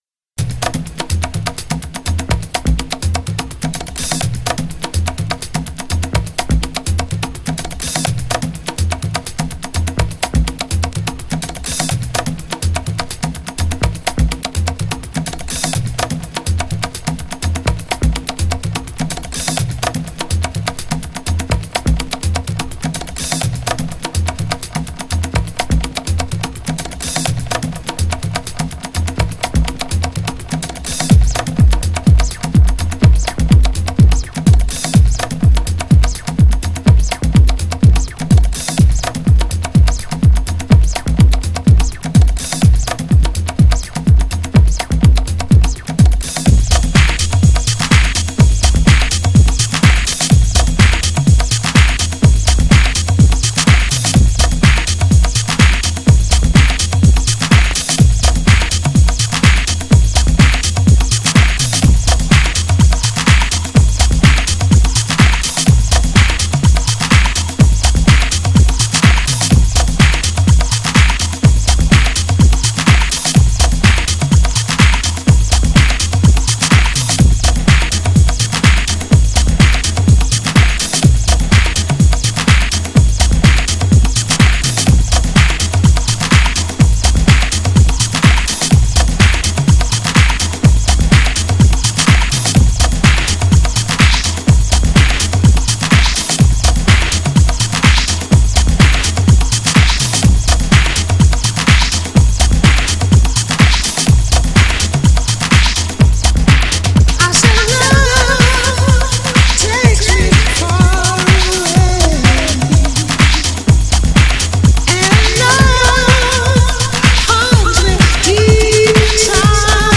プログレッシブなHOUSE!!
GENRE House
BPM 126〜130BPM